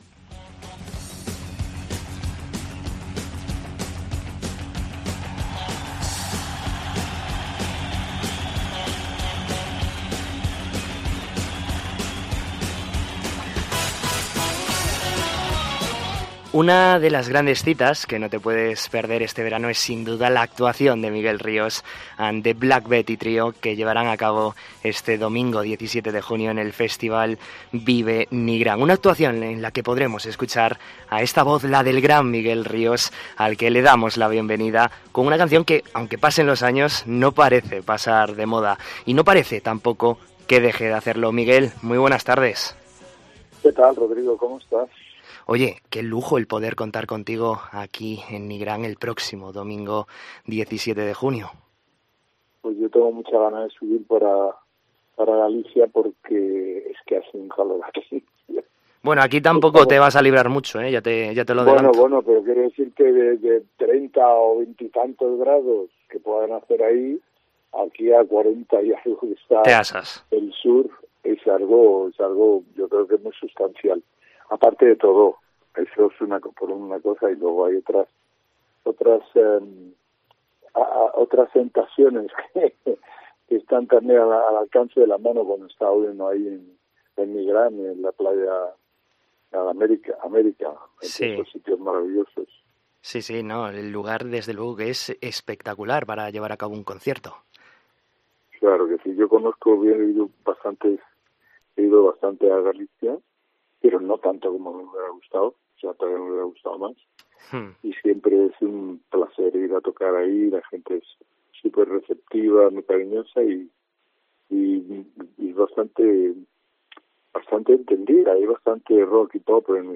AUDIO: En COPE Vigo hablamos con una de las grandes voces de la música española